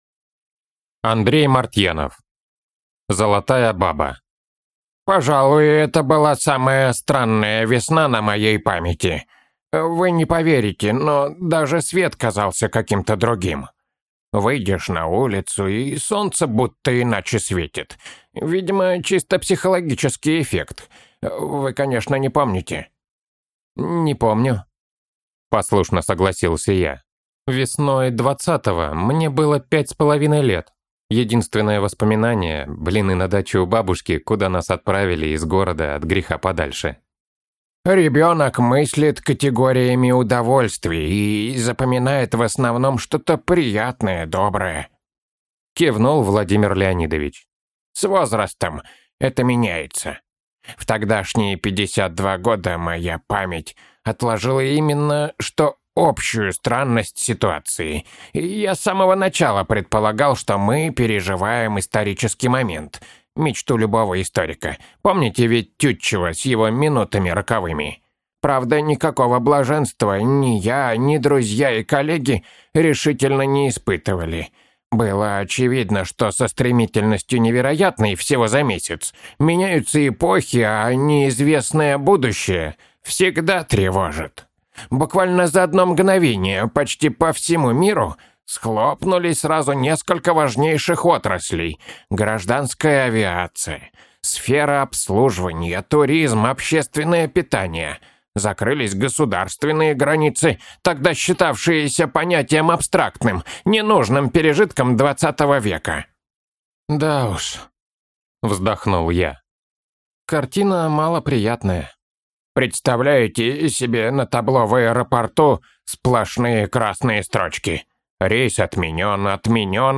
Аудиокнига Золотая баба | Библиотека аудиокниг